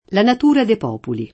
popolo [p0polo] s. m. — latinismo ant. populo [p0pulo]: la natura de’ populi [